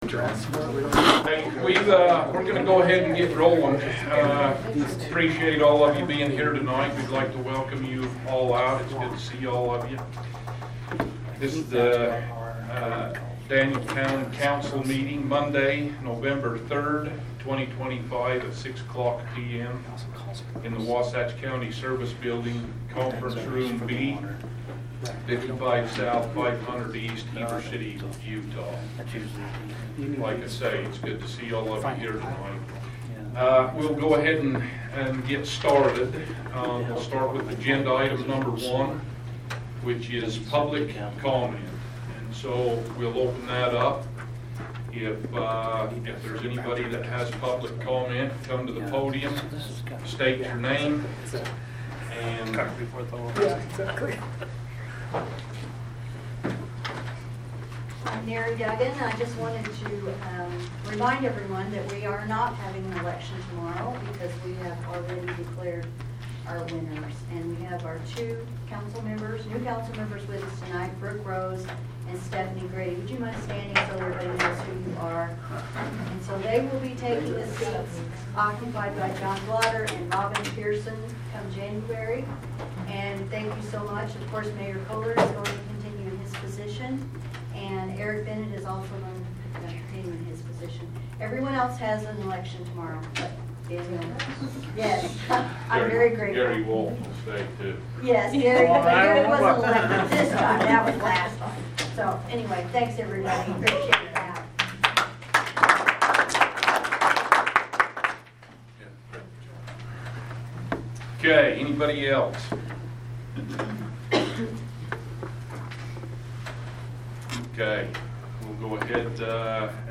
November 3, 2025 Town Council Meeting Audio